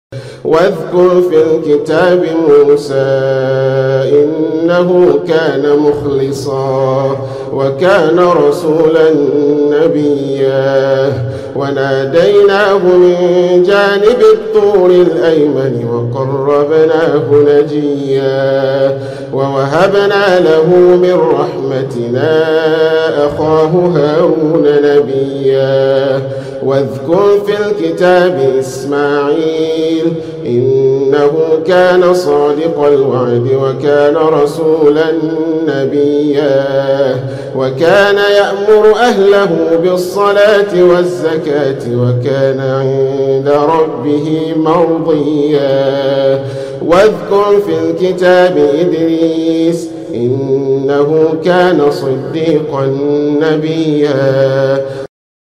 BEAUTIFUL RECITATION